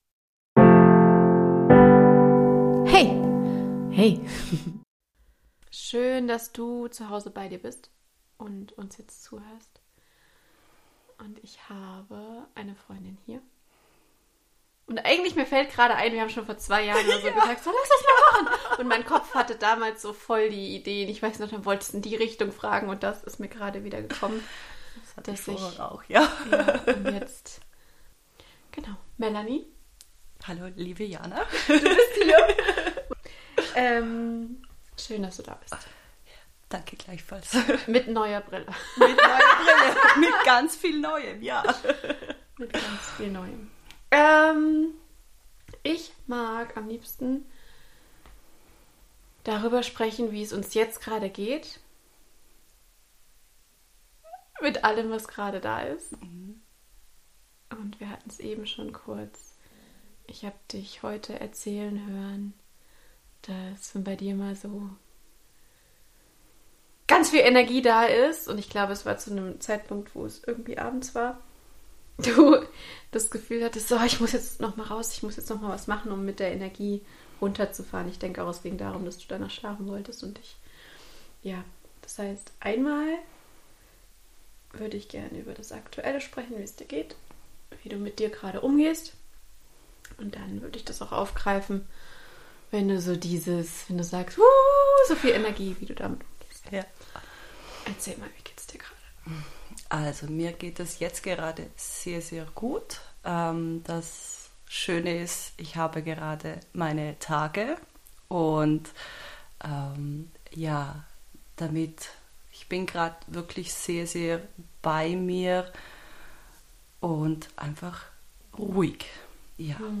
Zwei reflektierende Frauen, im spontanen Dialog.